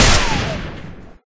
Gun3.ogg